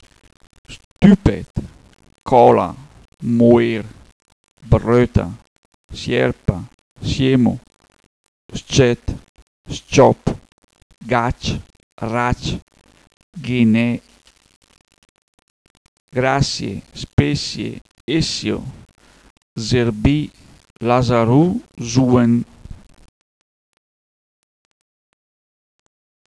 Clicca sulle parole qui sopra per sentire le parole in bergamasco utilizzate in questa pagina.